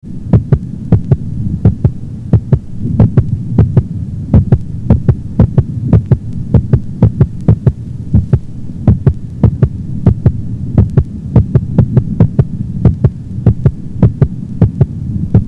Breed: Cavalier King Charles Spaniel
Direct HR: 100 to 120 beats/min
Heart Rhythm: Suspect respiratory sinus arhythmia
Intensity of Heart Sound: Normal
Extra Sounds – Clicks or Gallops: None
Heart Murmur: Grade 2-3/6 systolic murmur, loudest over the mitral area and left apex.